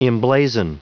Prononciation du mot emblazon en anglais (fichier audio)
Prononciation du mot : emblazon